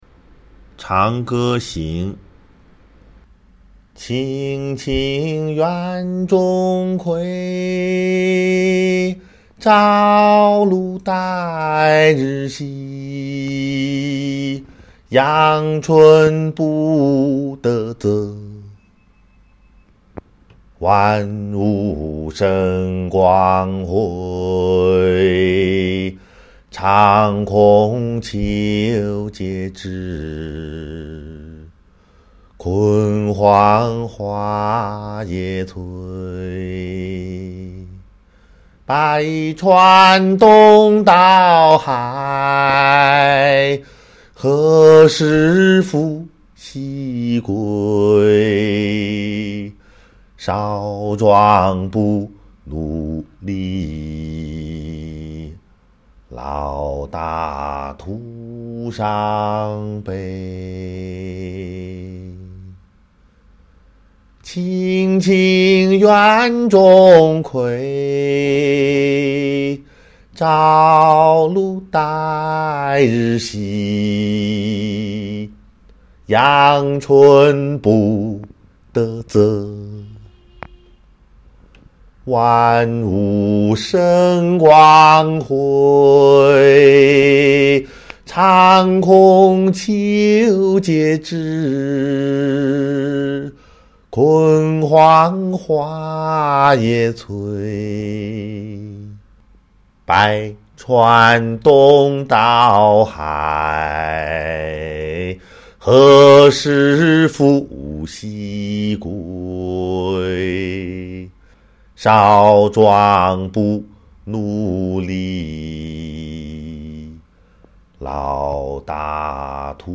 普通話吟誦